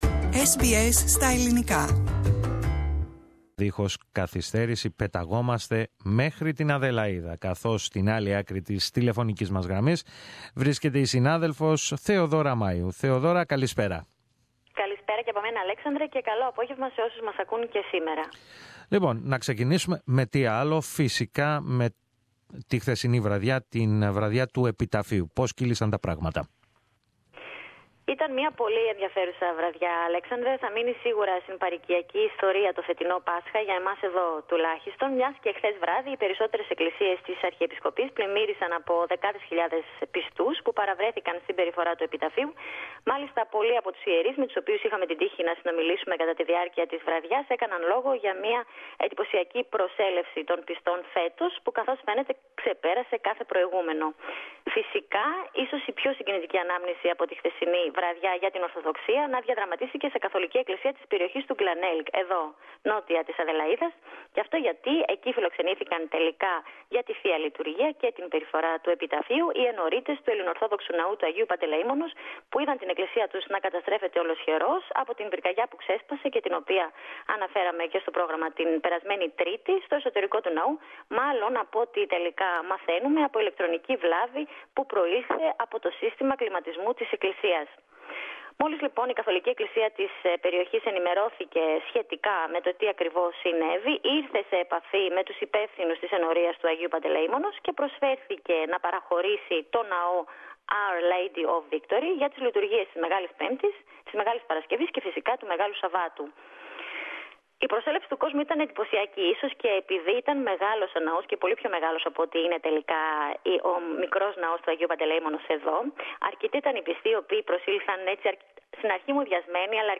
συνομιλία